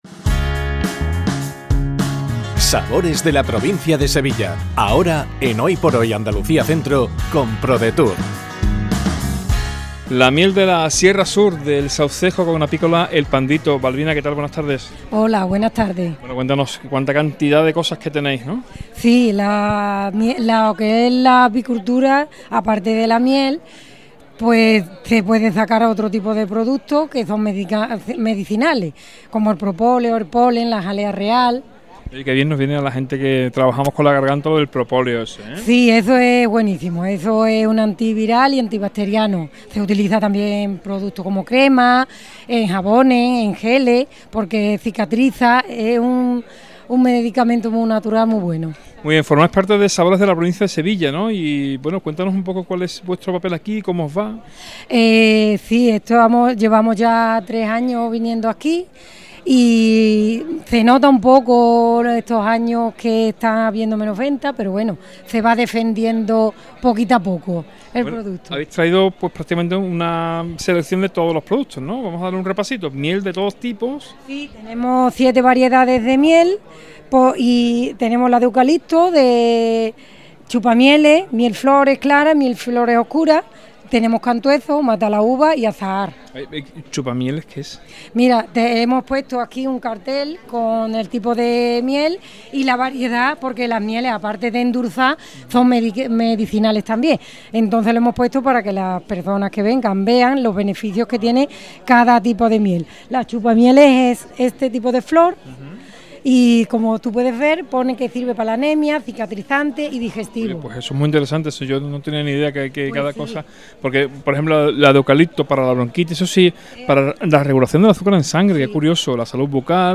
ENTREVISTA | PRODUCTOS APICOLA EL PANDITO